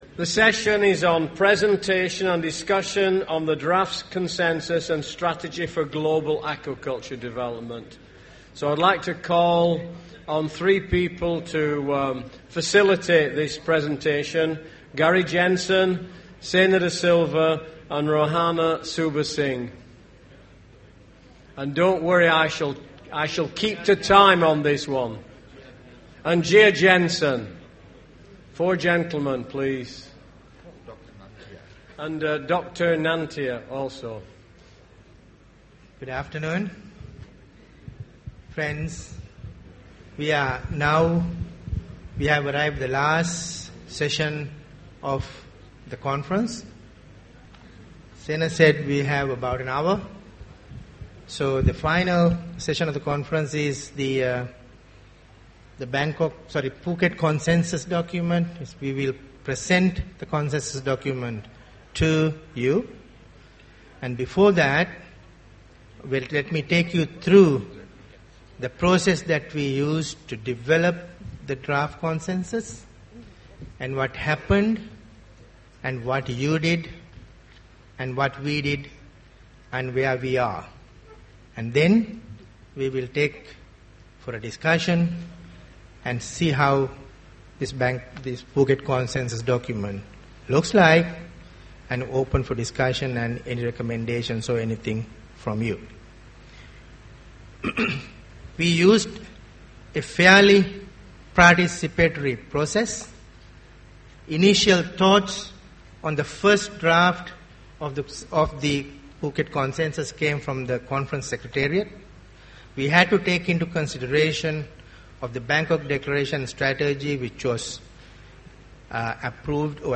Discussion on the draft Phuket Consensus
A presentation on the draft Phuket Consensus document as circulated with the conference papers a summary of comments that had been provided by conference participants. The presentation followed by a plenary discussion as the conference participants view the document on screen.